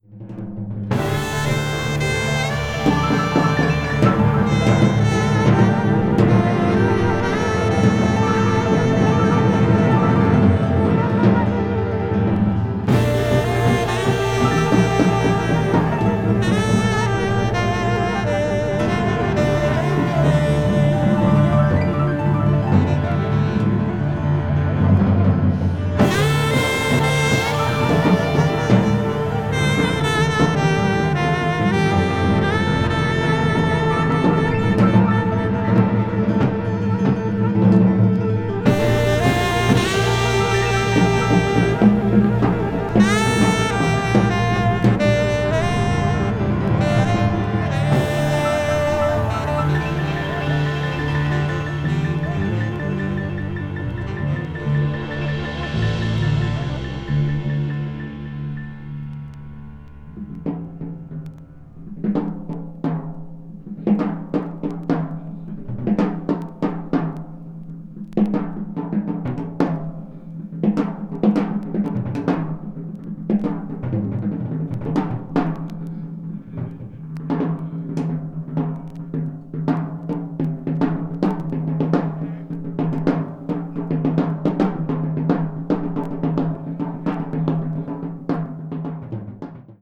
わずかにチリノイズが入る箇所あり
同年に新宿のライブ・ハウスPit Innで行われたコンサートのライヴ・レコーディング音源を収録。
非常に厚みのあるパワフルな演奏を聴かせてくれる熱気溢れるライヴ録音です。